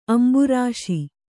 ♪ amburāśi